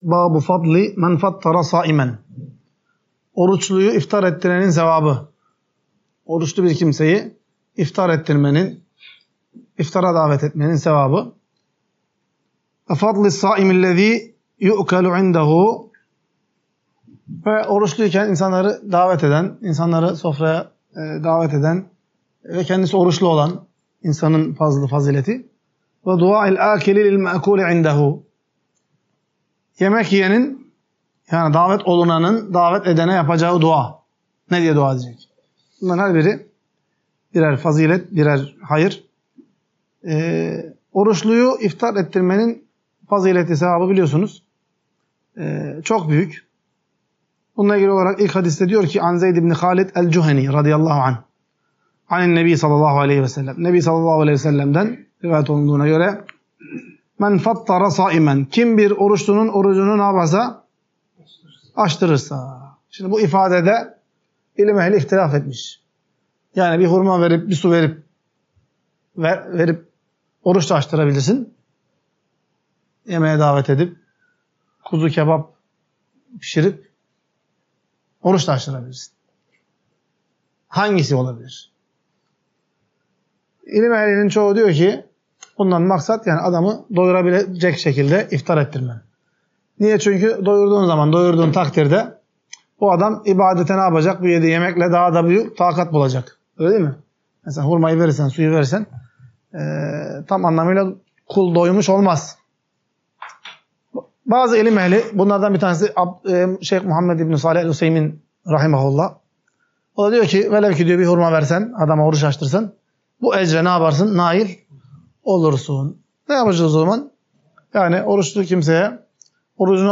Ders - 52. BÖLÜM | ORUÇLUYU İFTAR ETTİRMENİN FAZİLETİ, YANINDA YEMEK YENEN ORUÇLUNUN SEVABI VE YEMEK YİYENİN, YEDİRENE DUA ETMESİ